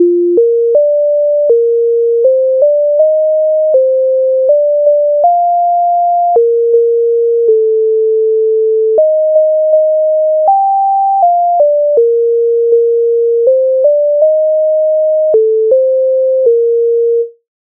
Лугом іду коня веду Українська народна пісня